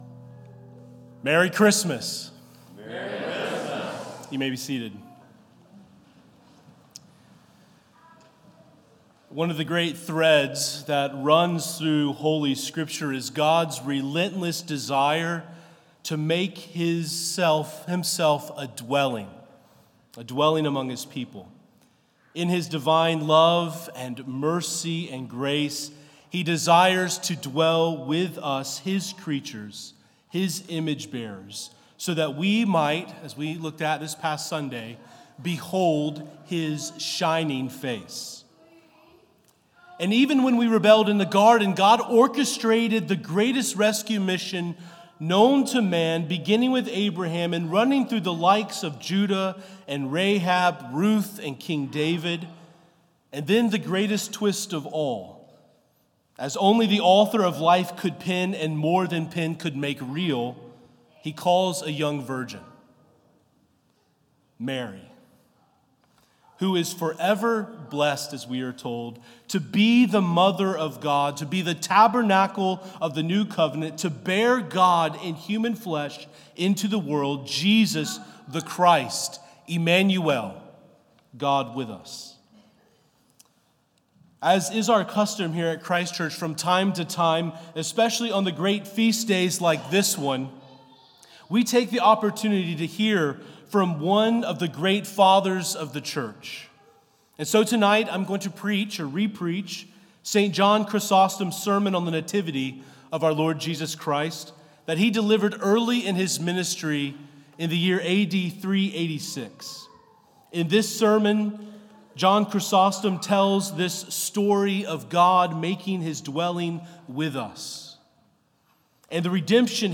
Isaiah 62:6-12 Psalm 97 Titus 3:4-7 Luke 2:1-20 St. John Chrysostom's Sermon on the Nativity of our Lord